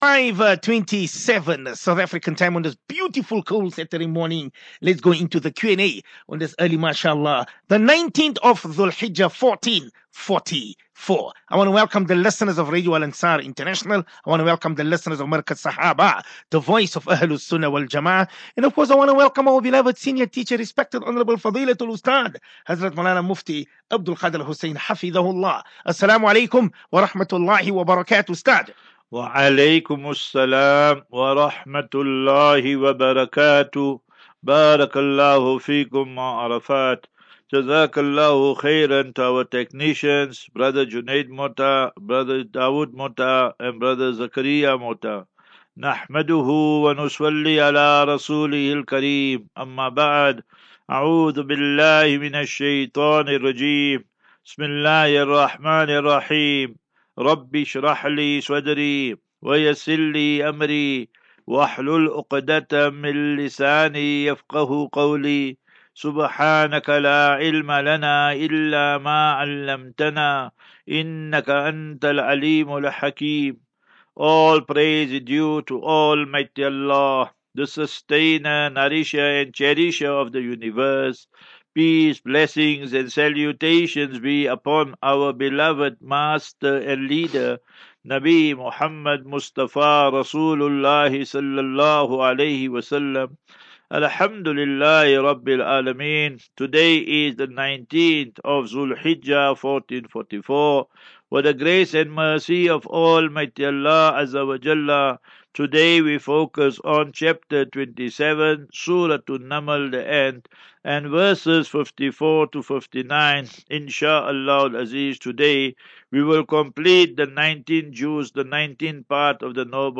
As Safinatu Ilal Jannah Naseeha and Q and A 8 Jul 08 July 2023.